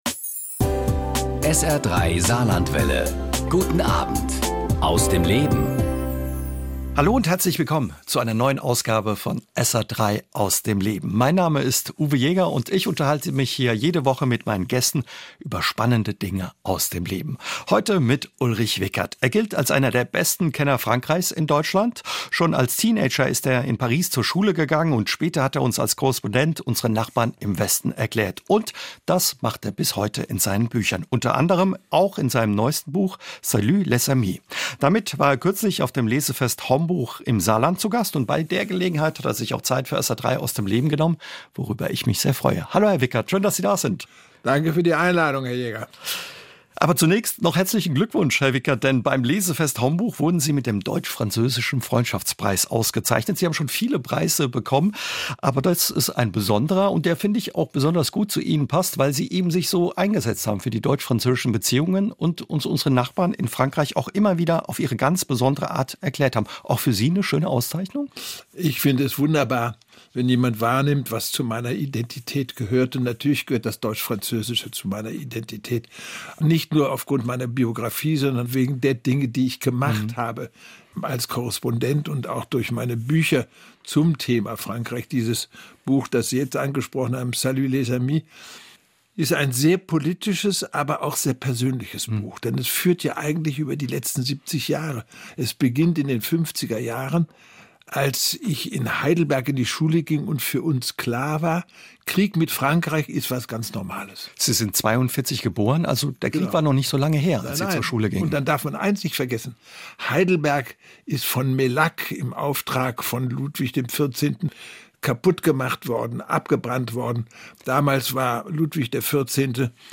mit dem Journalisten und Autor Ulrich Wickert ~ Aus dem Leben Podcast